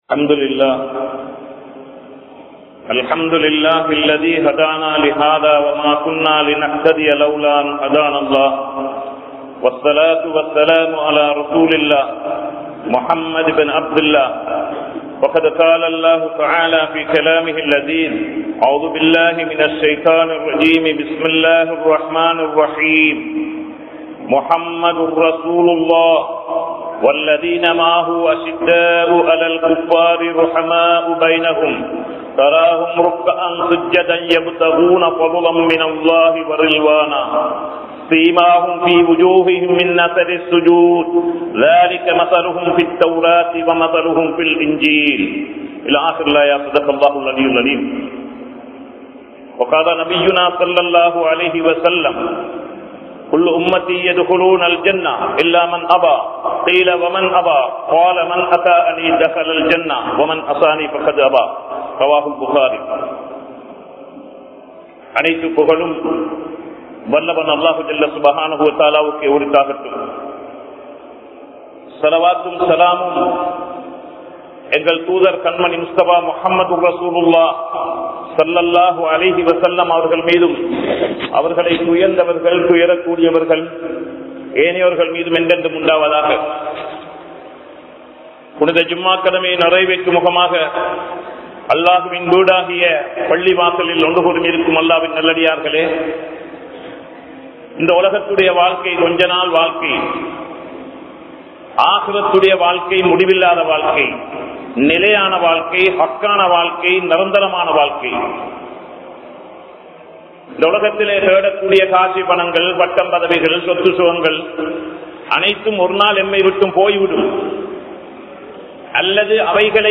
Nabi(SAW)Avarhalin Sirappuhal (நபி(ஸல்)அவர்களின் சிறப்புகள்) | Audio Bayans | All Ceylon Muslim Youth Community | Addalaichenai
Grand Jumua Masjith